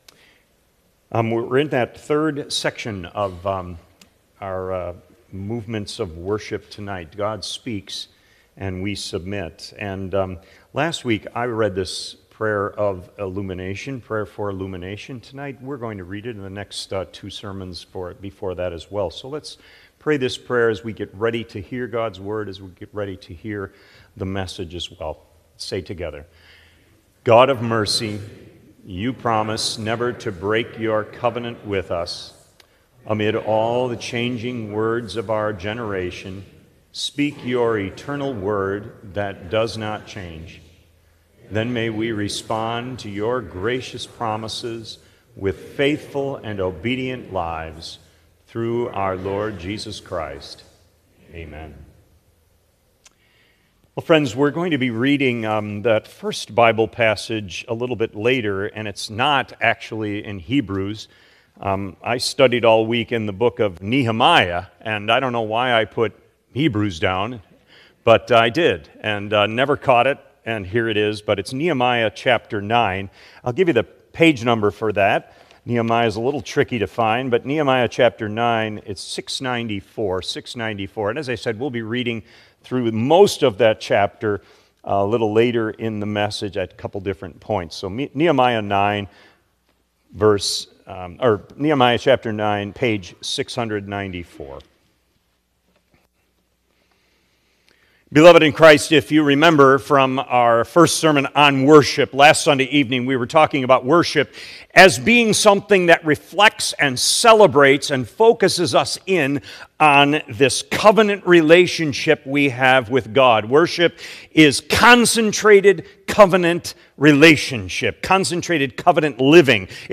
Sermon Recordings | Faith Community Christian Reformed Church
“God Commands, We Confess” November 9 2025 P.M. Service